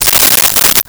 Coins In Hand 02
Coins in Hand 02.wav